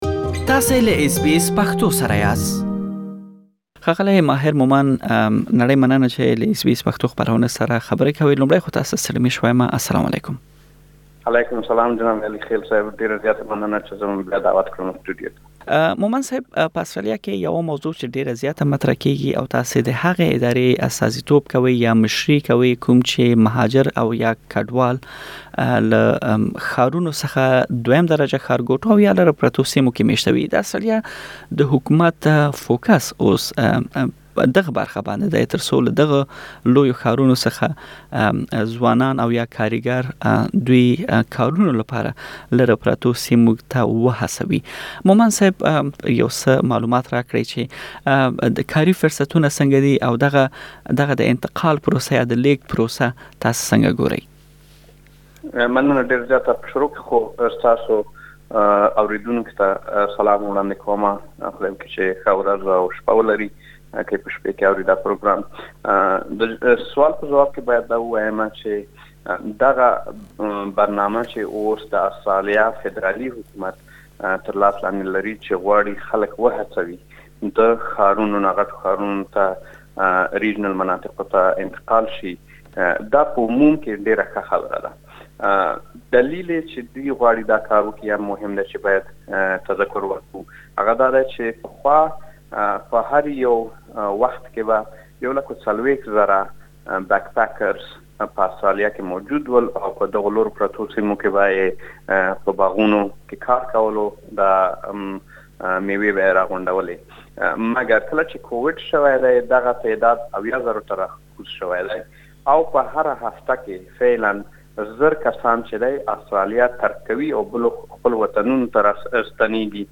تاسې بشپړه مرکه دلته اوريدلی شئ.